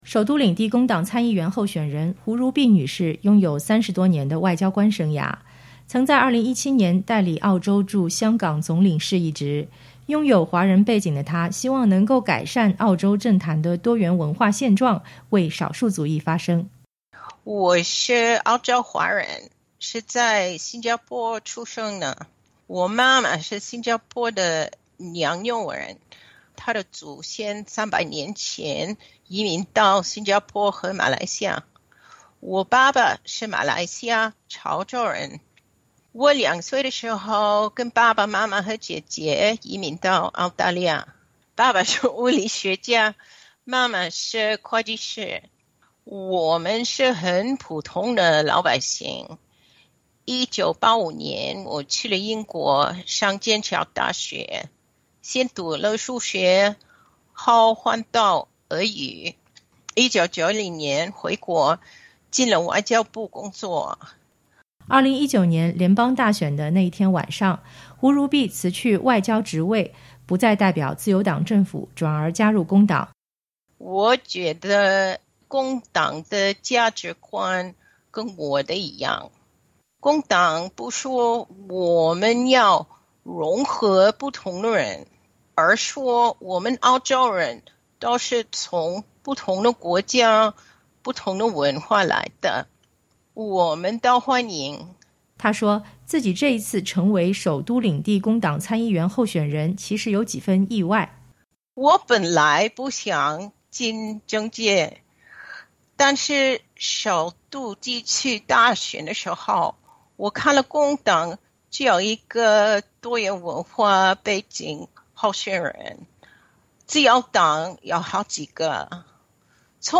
胡如碧（Janaline Oh）女士在接受SBS普通話寀訪時說。